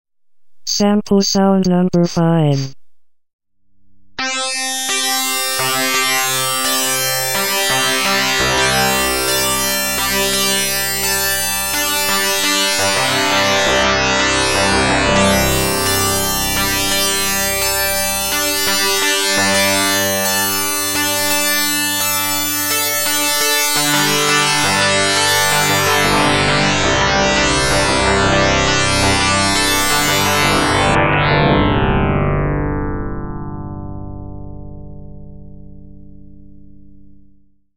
●FM音と自己相似系による自動演奏サンプル（
この例では、 5拍子のたった3音からなるフレーズを元にして、自己相似アルゴリズムで 移調したフレーズを重ねた演奏を実現するスクリプトに基き、シタールに 似た音色の8音ポリフォニックFM音源として自動演奏するように設定 してある。